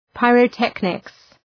Προφορά
{,paırə’teknıks}